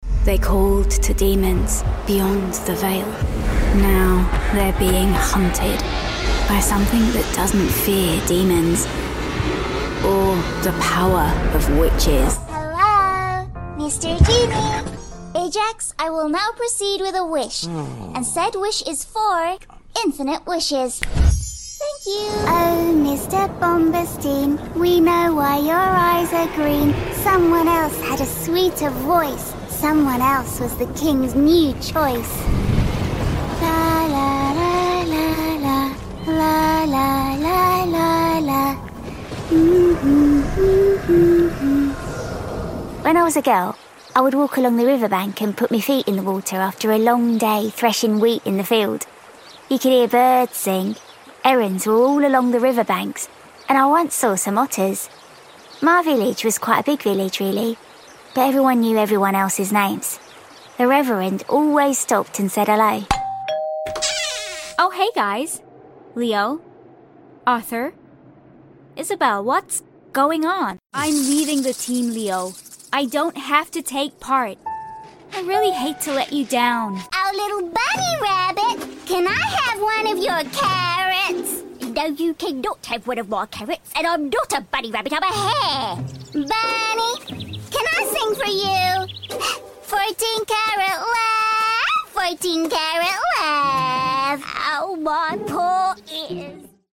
British, natural, modern, young adult and teen voice
CHARACTER Reel
British, English, RP, London, Cockney, Indian-English
A lot of it was recorded in my pro home studio.